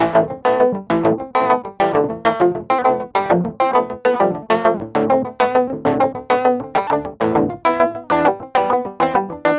四和弦吉他Ac 2
描述：电吉他
Tag: 100 bpm Pop Loops Guitar Electric Loops 826.98 KB wav Key : A